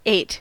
Ääntäminen
Etsitylle sanalle löytyi useampi kirjoitusasu: ate ATE Ääntäminen US : IPA : /eɪt/ UK : IPA : /eɪt/ UK : IPA : /ɛt/ Haettu sana löytyi näillä lähdekielillä: englanti Käännöksiä ei löytynyt valitulle kohdekielelle.